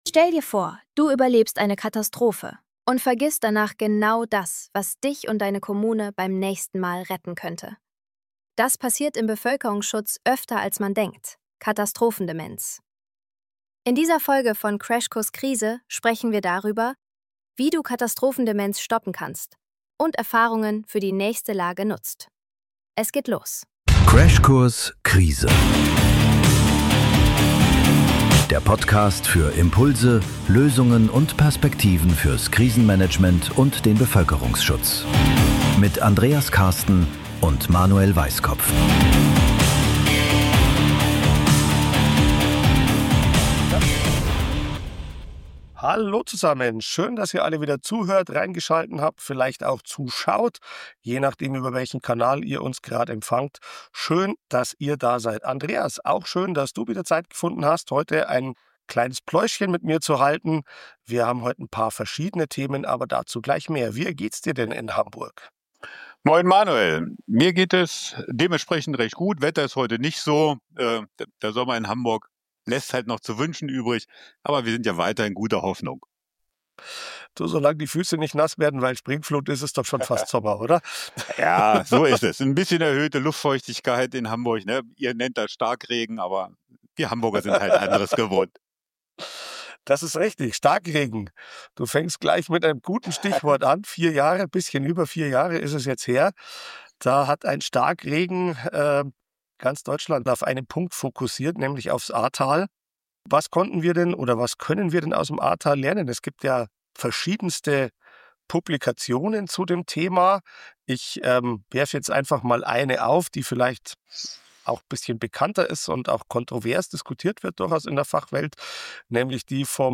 Ein praxisorientiertes Gespräch, das zeigt: Katastrophendemenz ist kein Schicksal – sie lässt sich stoppen.